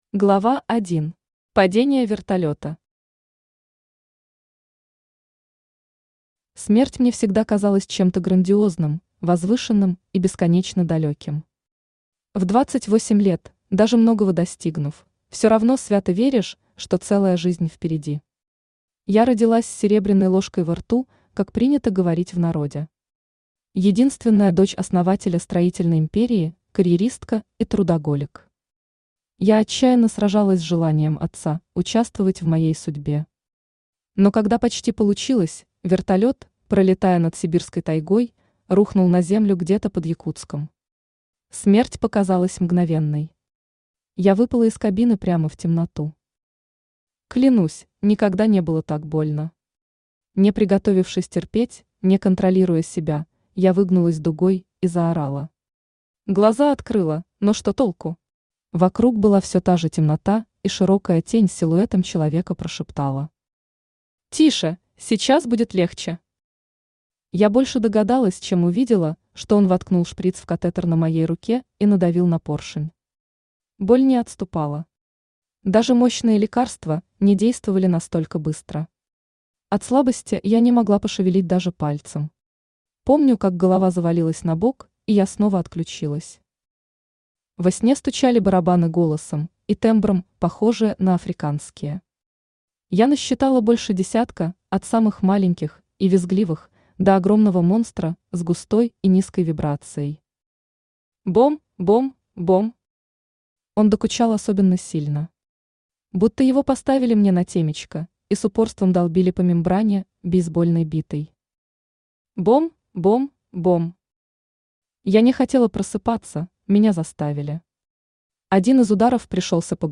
Аудиокнига Шаман | Библиотека аудиокниг
Aудиокнига Шаман Автор Дэлия Мор Читает аудиокнигу Авточтец ЛитРес.